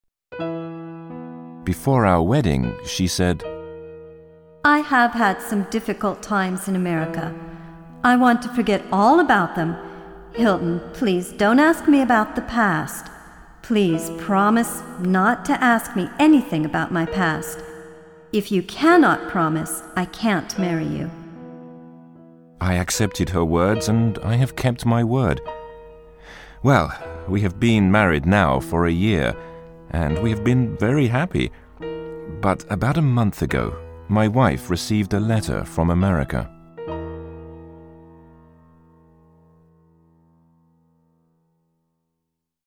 音声には効果音も取り入れていますので、学習者が興味を失わずに最後まで聴き続けることができます。